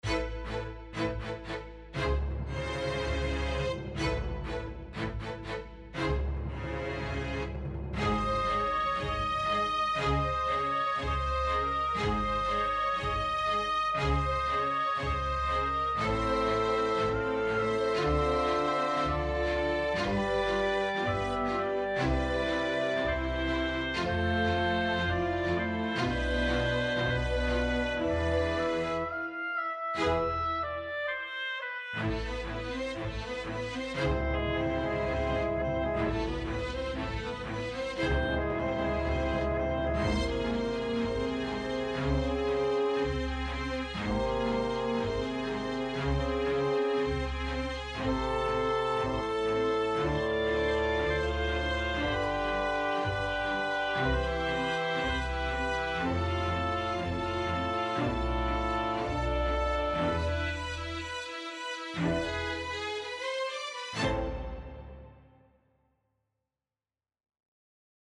1’08 BPM: 120 Description
Underscore Reduced arrangement